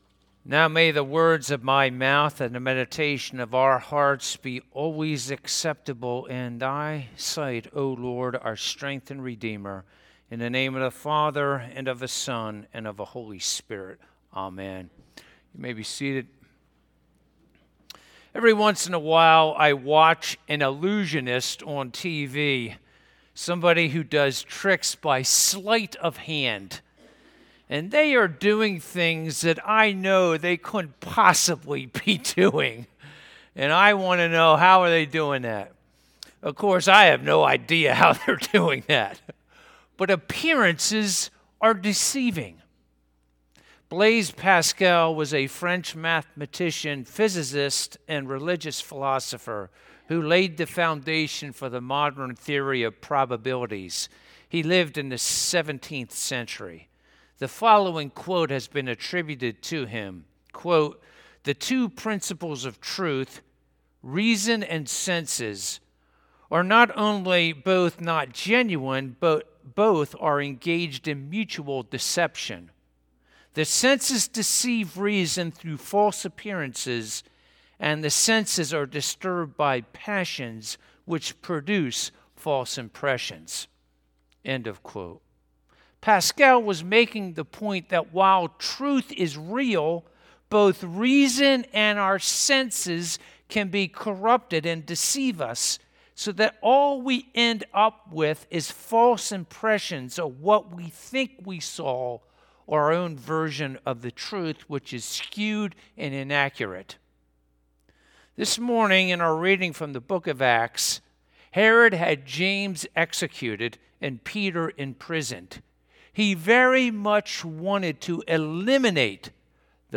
Bible Text: Acts 12:1-25 | Preacher